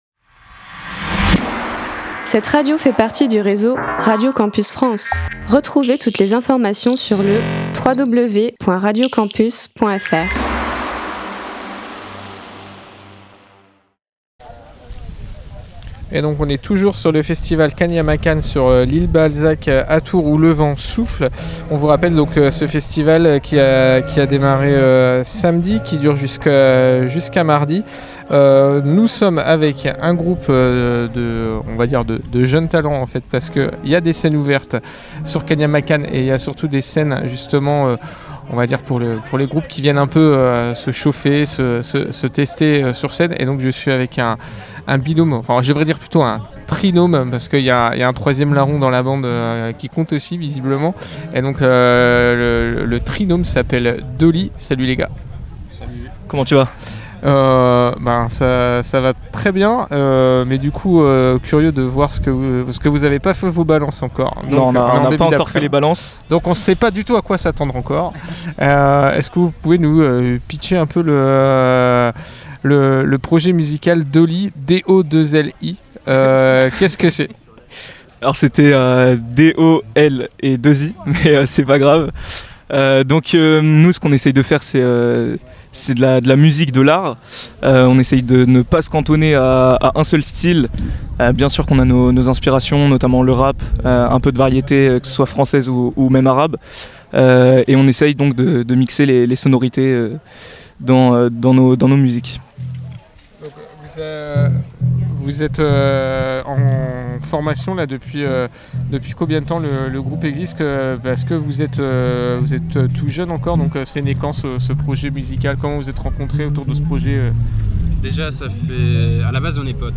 On a pu se promener avec nos micros sur le festival et réaliser quelques interviews que nous vous proposons ici en podcasts.
L’interview de Dolii, un duo de rappeurs qui fait ses premières armes sur scène:
itw_dolii.mp3